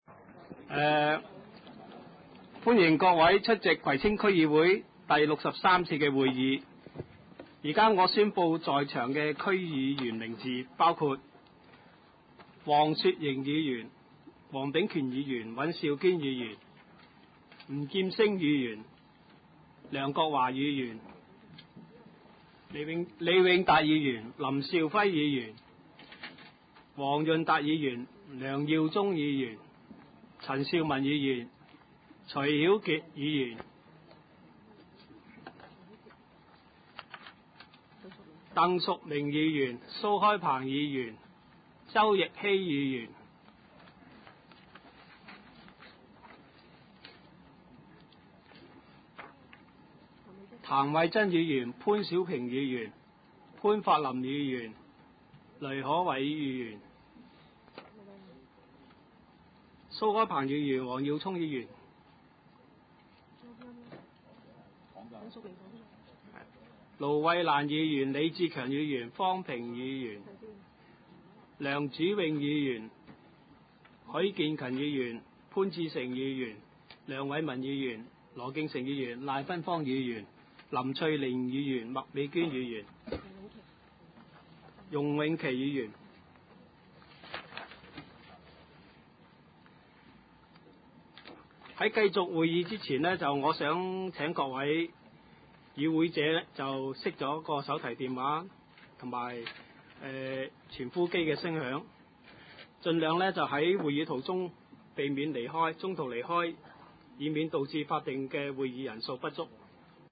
葵青區議會第六十三次會議
葵青民政事務處會議室
開會詞